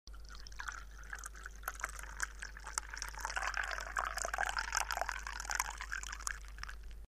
Звуки стакана
Звук наливания воды в стеклянный стакан